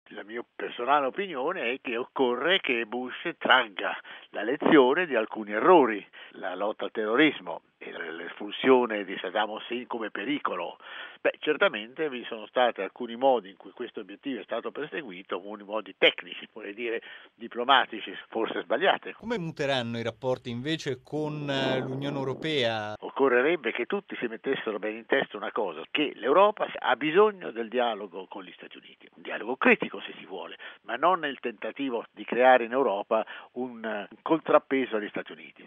Ci si chiede ora quanto il secondo mandato di Bush potrà divergere dal primo? Risponde l’ambasciatore Luigi Vittorio Ferraris, docente di Relazioni internazionali all’Università Roma-Tre: